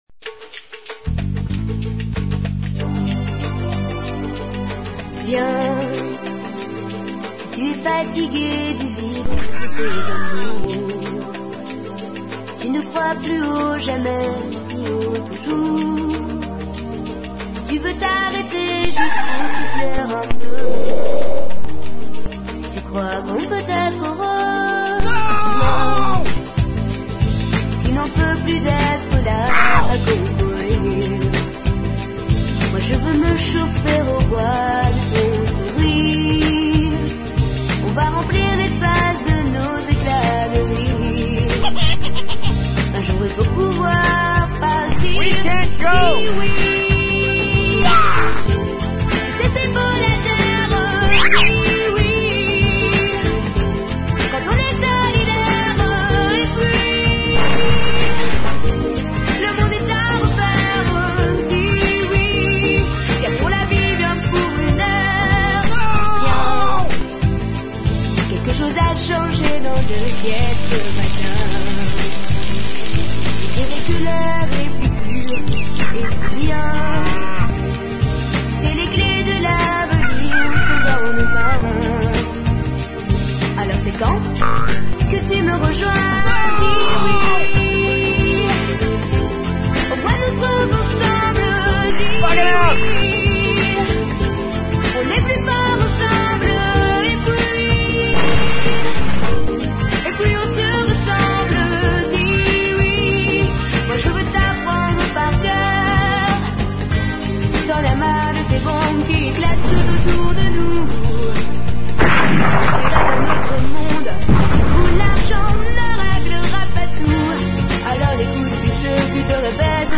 voici un petit remix maison de son plus grand succ�s